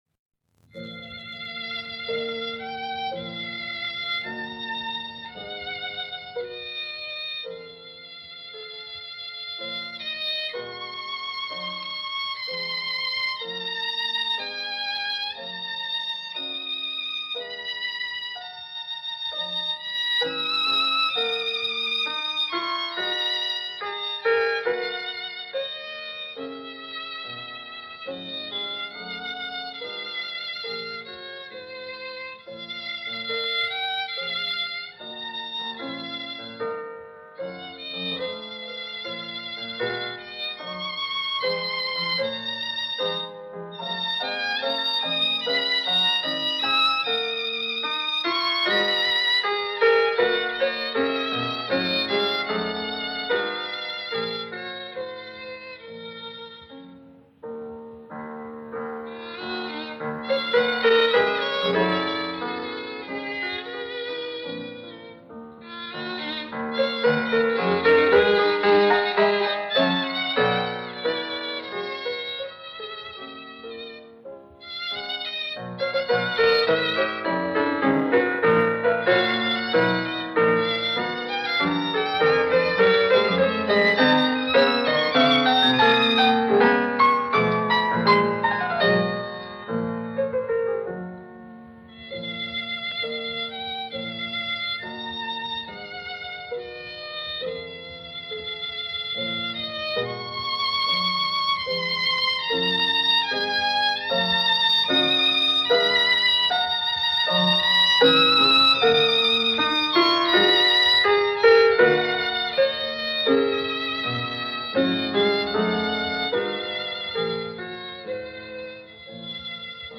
Ruhig gehend   (2'23" - 2,2MB)